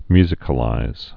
(myzĭ-kə-līz)